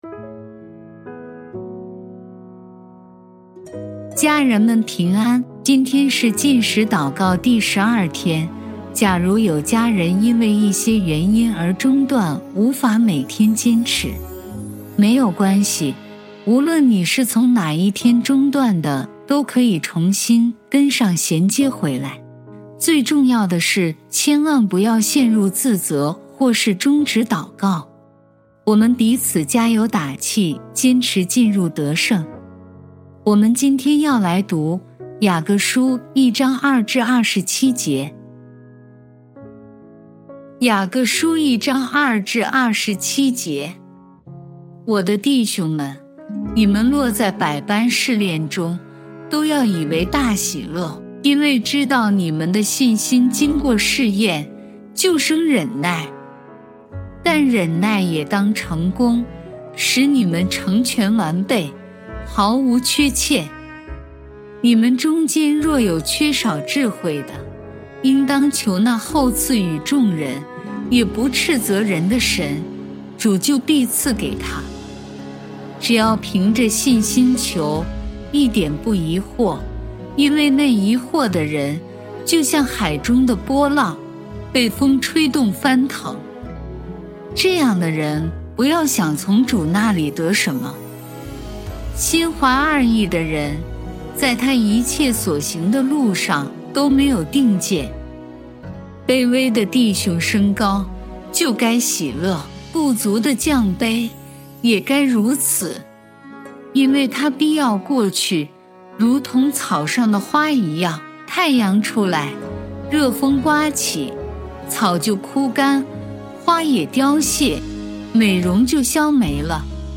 本篇是由微牧之歌撰写及录音朗读 第12天 我们是已经干净的 第一音源 第二音源 家人们平安 今天是禁食祷告...